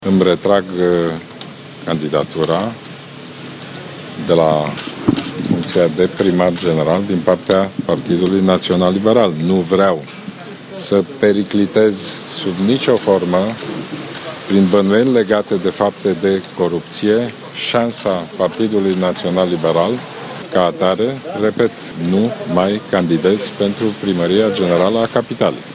La ieșirea din sediul Direcției Anticorupție, politicianul liberal a anunțat că îşi retrage candidatura și că demisionează din funcţiile de prim-vicepreşedinte PNL şi de vicepreşedinte al Camerei Deputaţilor: